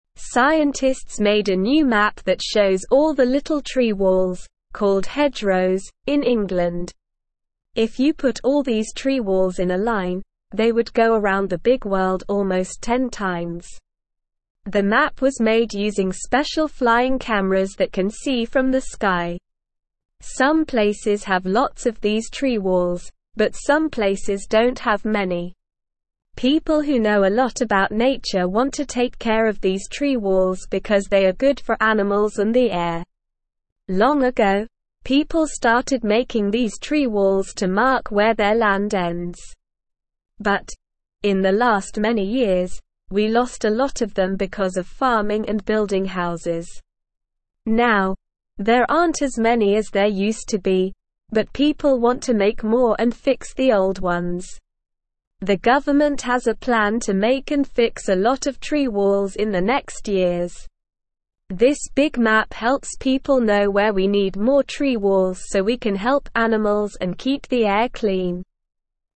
Slow
English-Newsroom-Beginner-SLOW-Reading-New-Map-Shows-Tree-Walls-in-England.mp3